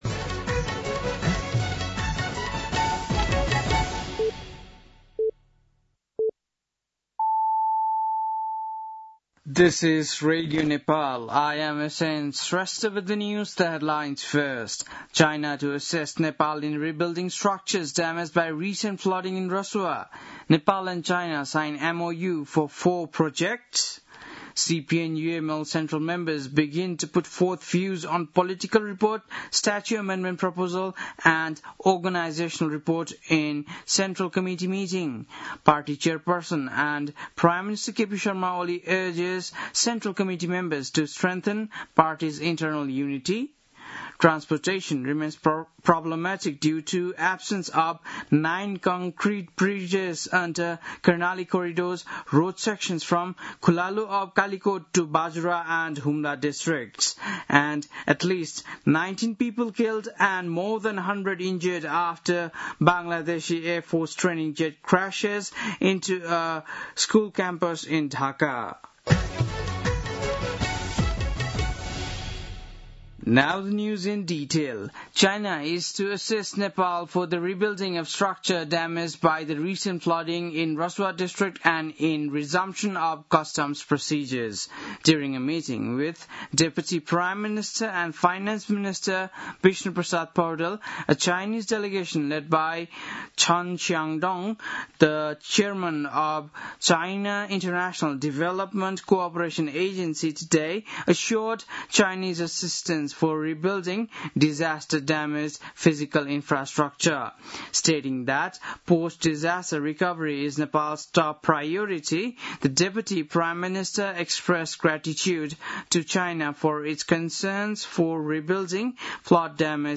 बेलुकी ८ बजेको अङ्ग्रेजी समाचार : ५ साउन , २०८२
8-pm-english-news-4-05.mp3